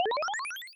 level up coot v2.wav